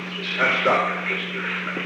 On May 13, 1971, President Richard M. Nixon and unknown person(s) met in the Oval Office of the White House at an unknown time between 6:03 pm and 6:05 pm. The Oval Office taping system captured this recording, which is known as Conversation 498-017 of the White House Tapes.